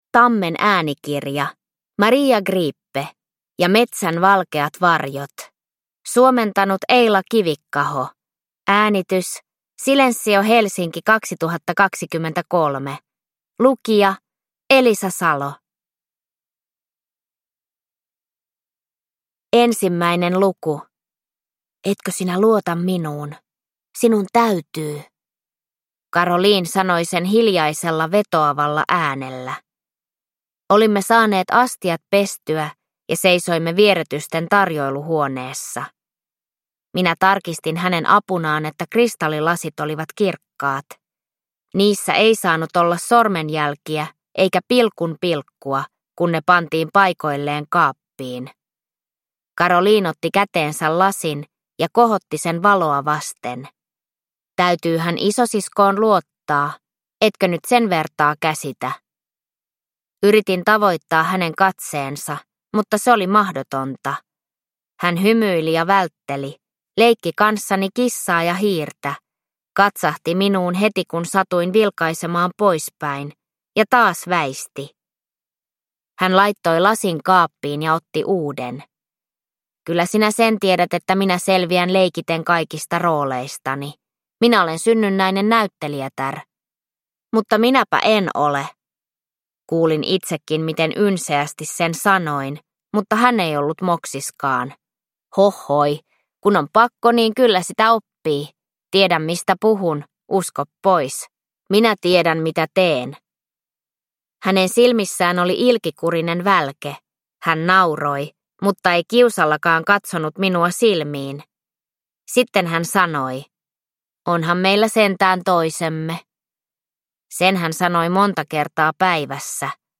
...ja metsän valkeat varjot – Ljudbok – Laddas ner